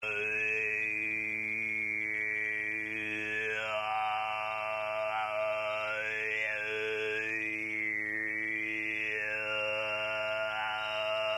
Throat-Singing Audio Clips 10 secs, 80 kBytes, format:MPEG Audio Layer-3.